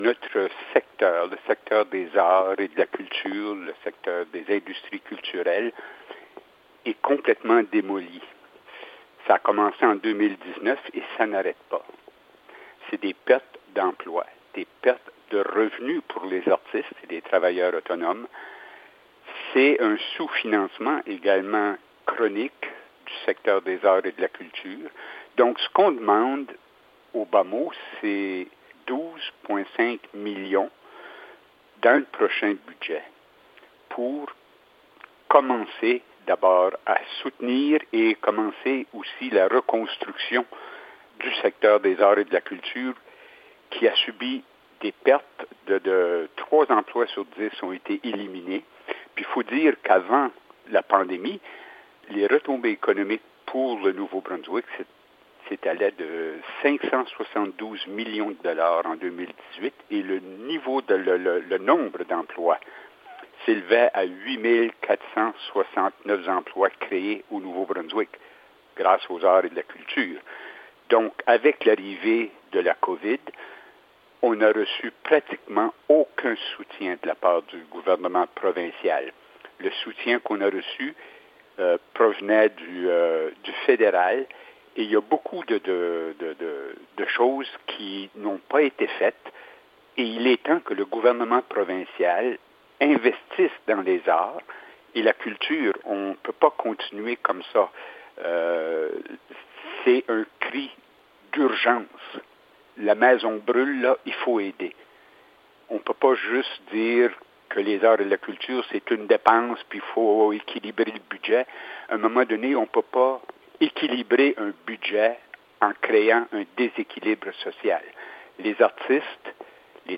en parle davantage en entrevue au 90.5 FM